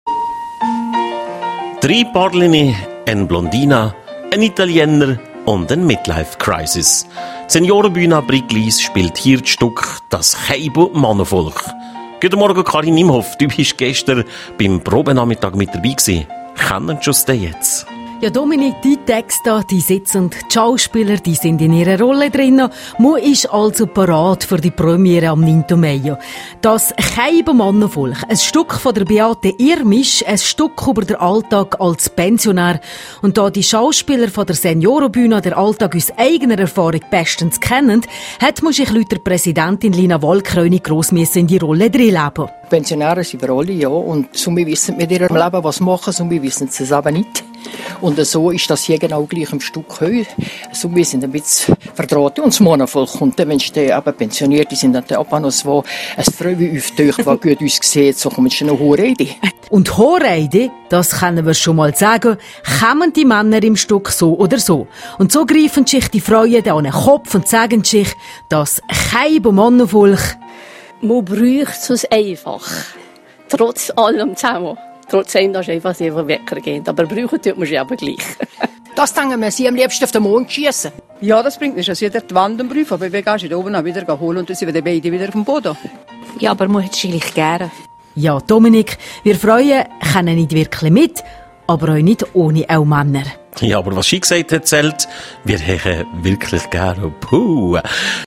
Endspurt bei den Proben der Seniorenbühne Brig-Glis.